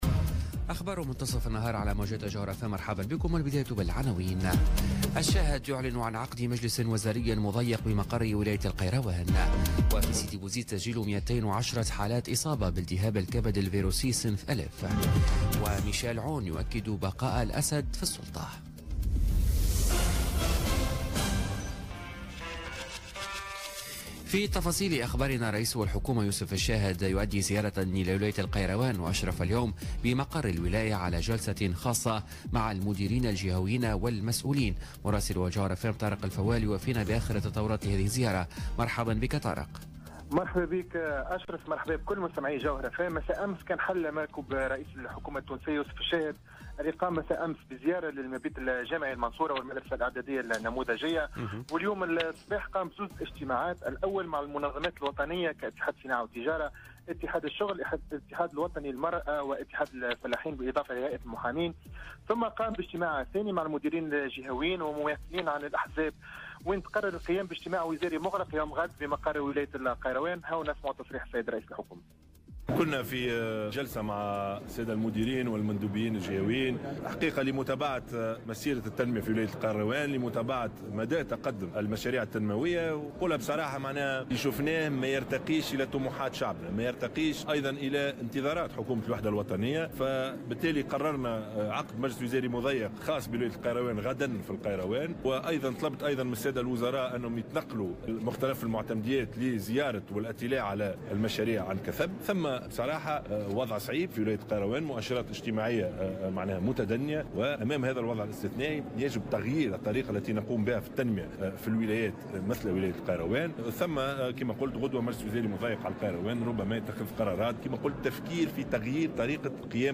نشرة أخبار منتصف النهار ليوم الأربعاء 29 نوفمبر 2017